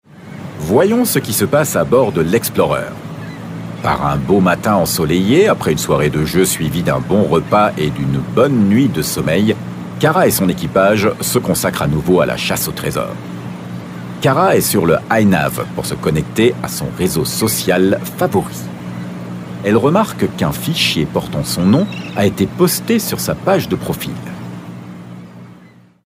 Dank seiner angenehmen Tonlage, nicht zu tief und auch nicht zu hell, ist er sehr flexibel einsetzbar.
Kinderfilm FR
Kinderfilm-FR.mp3